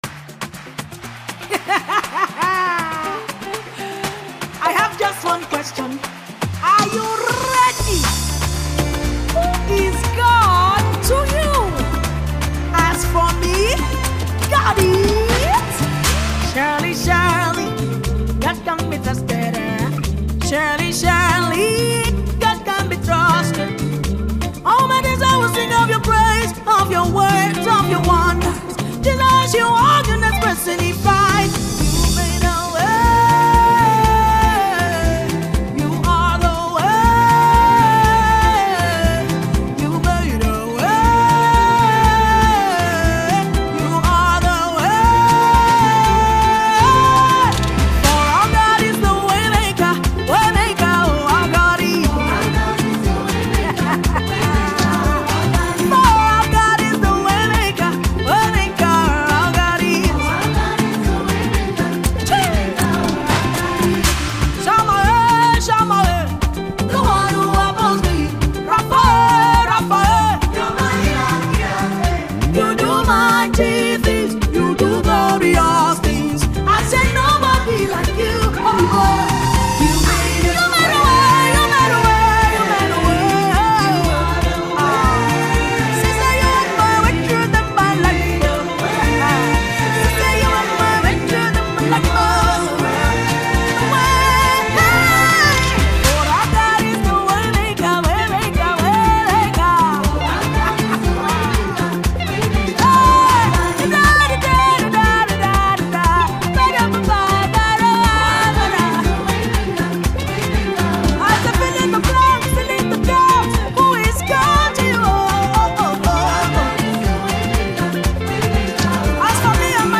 Talented Gospel singer and songwriter